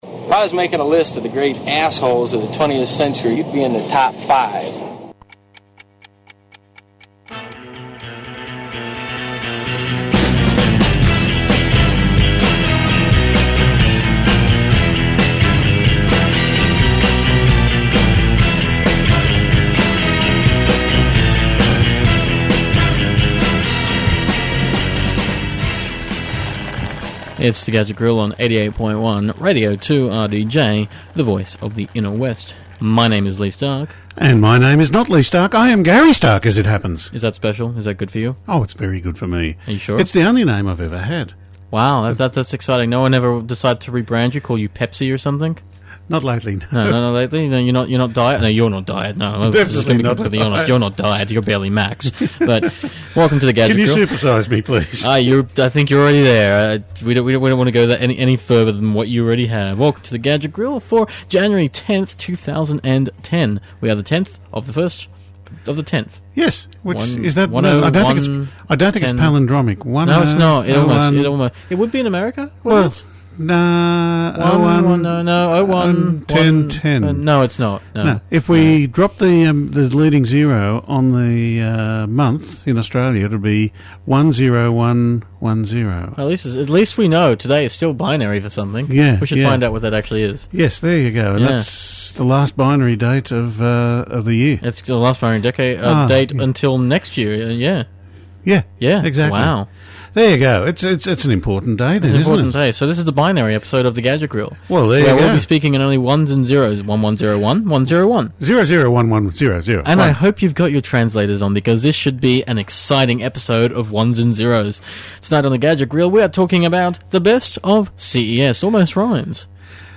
Podcast: Play in new window | Download (17.4MB) Subscribe: RSS Here at The Gadget Grill, we aim to provide you with an entertaining show packed with great music.